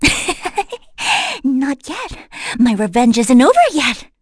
FreyB-Vox_Victory.wav